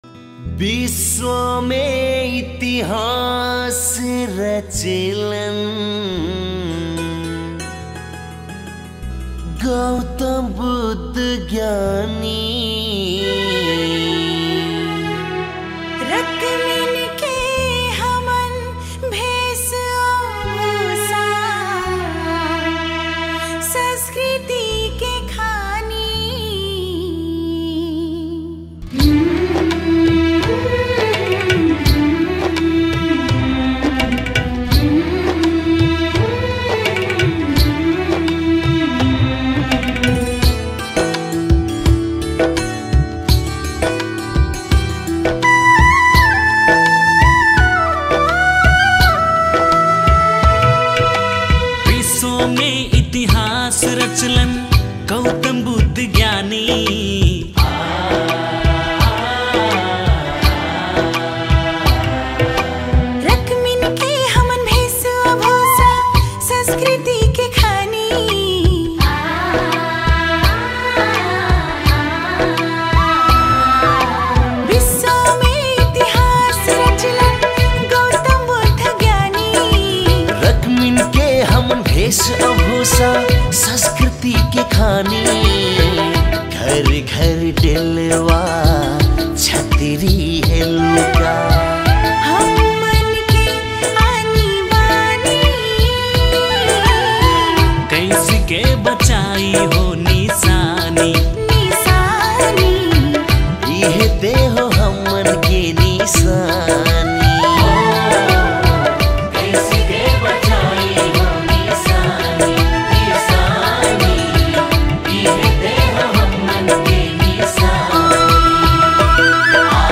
Tharu Culture Song